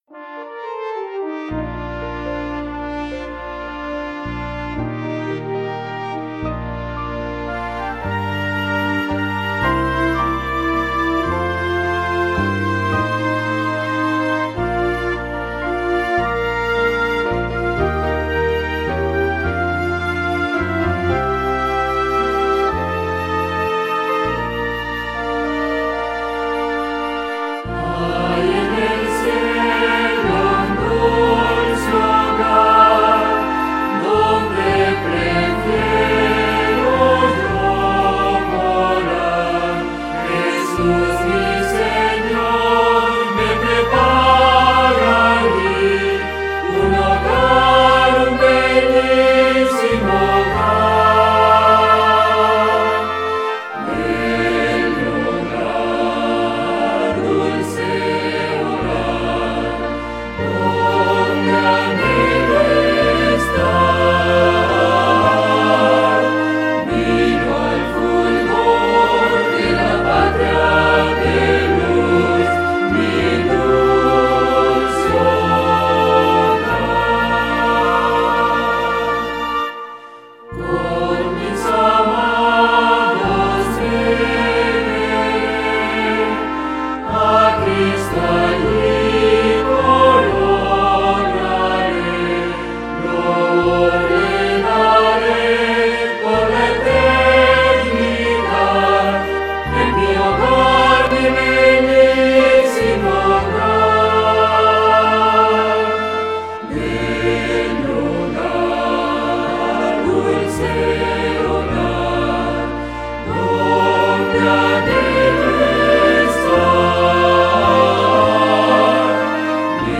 Mi hogar celestial - Cantado | Iglesia Adventista | Melocotón Play